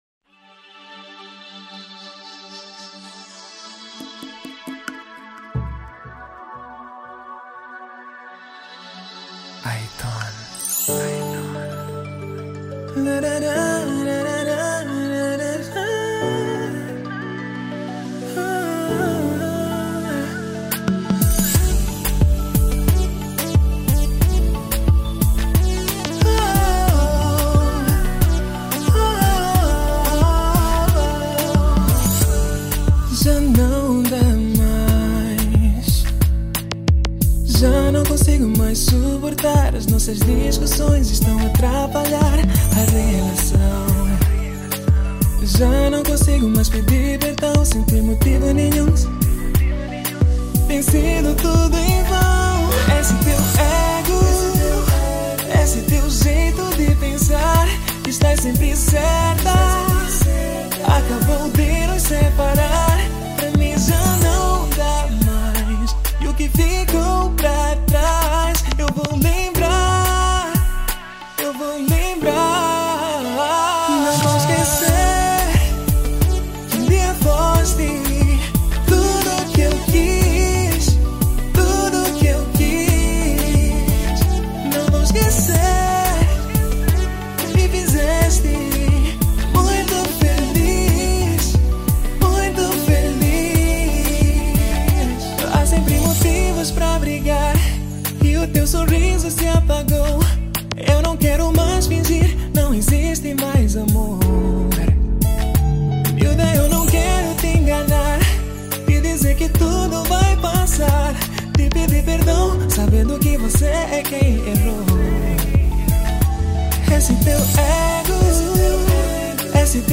Kizomba moçambicana